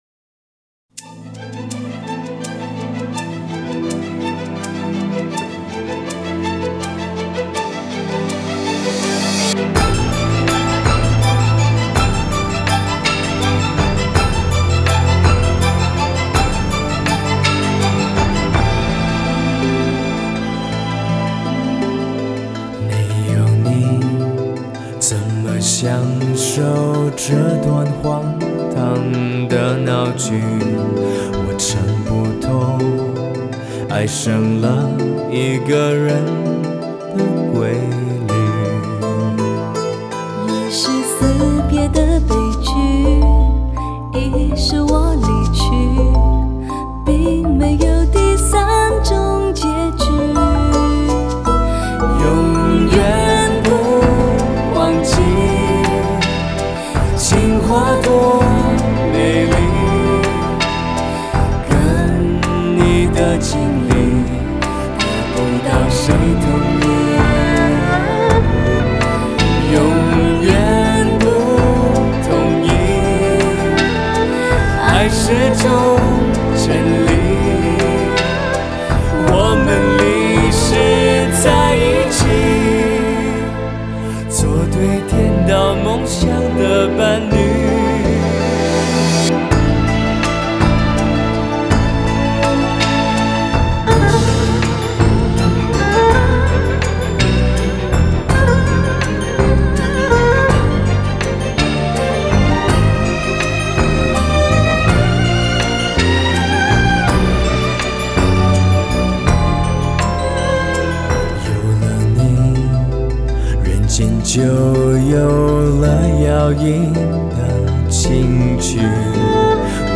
气势磅礴,扣人心弦的电影原声乐及主题曲